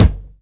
• Acoustic Kick G Key 309.wav
Royality free kick drum sample tuned to the G note. Loudest frequency: 561Hz
acoustic-kick-g-key-309-H4z.wav